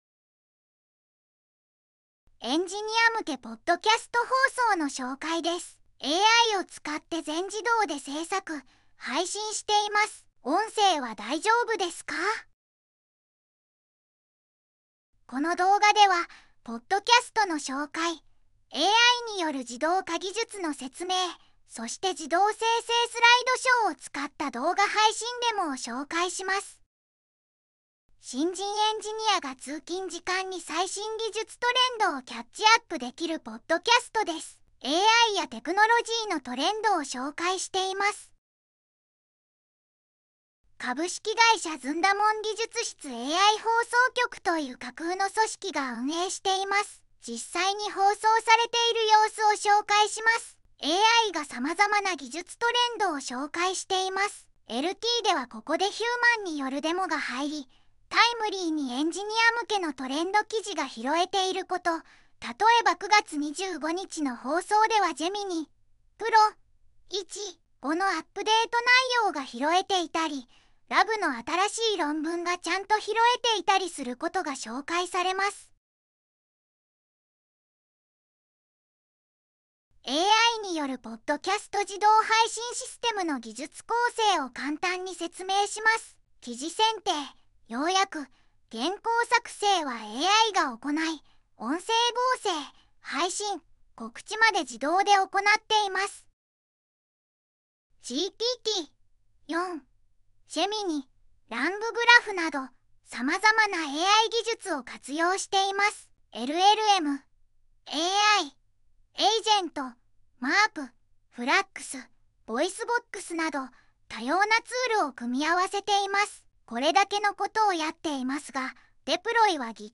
社内LT会にずんだもん先輩がpodcast放送の紹介で登壇してきました。